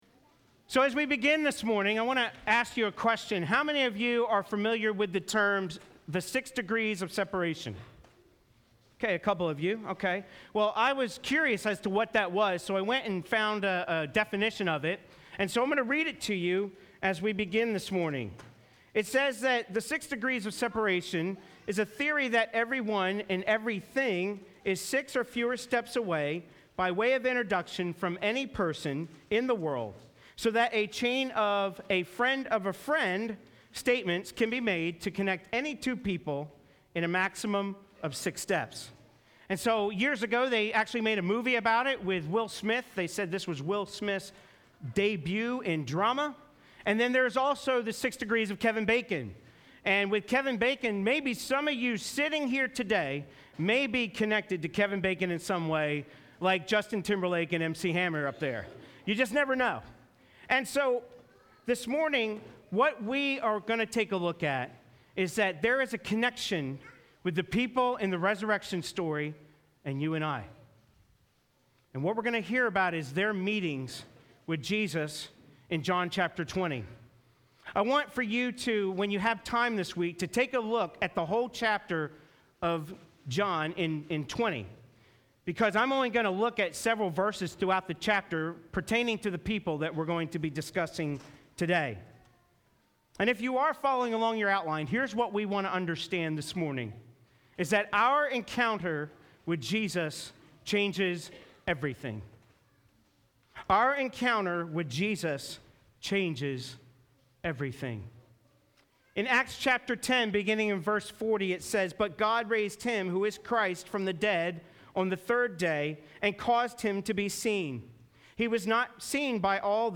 Easter Sunday 2014